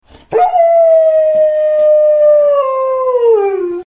Dog Howling 04 Sound Button - Free Download & Play